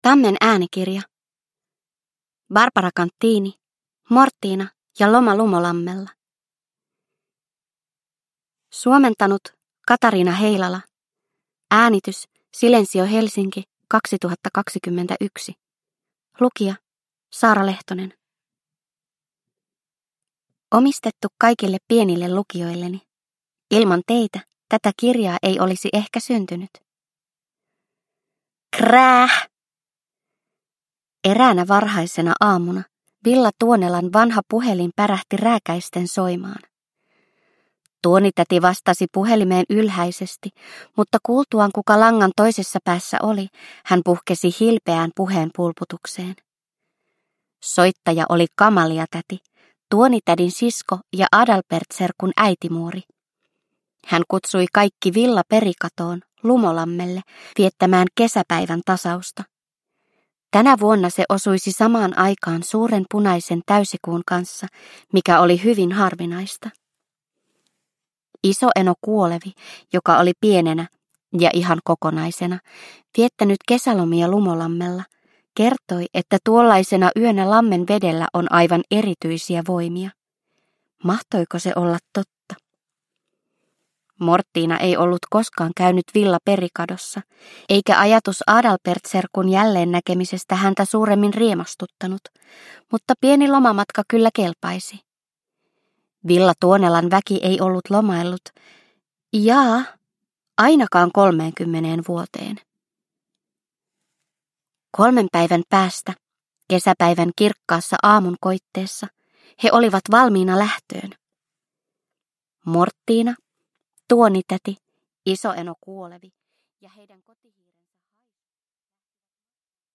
Mortina ja loma Lumolammella – Ljudbok – Laddas ner